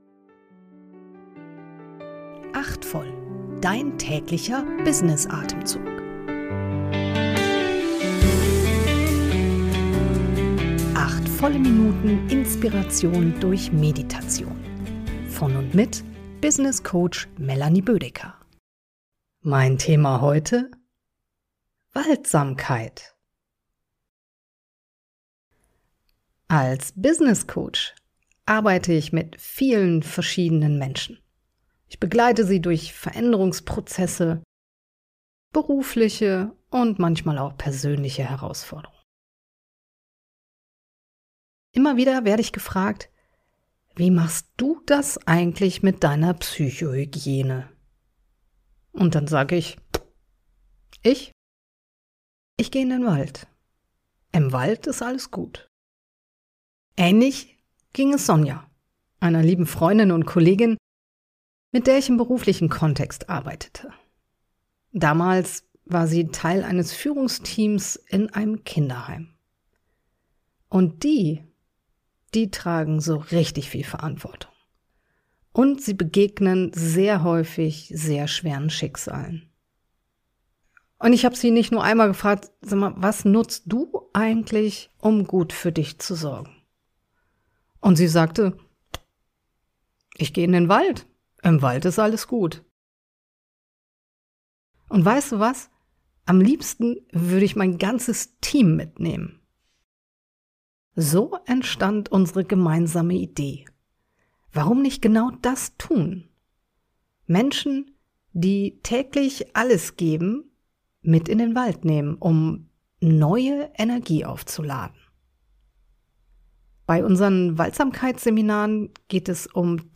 geleitete Kurz-Meditation.